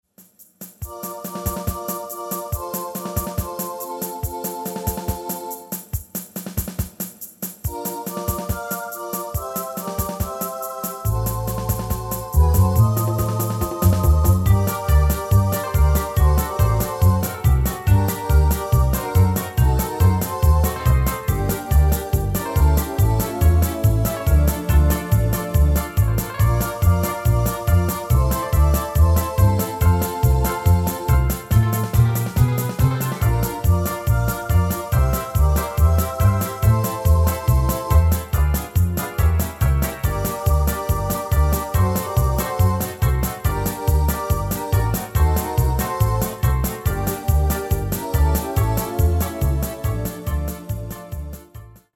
Rubrika: Pop, rock, beat
- směs
Karaoke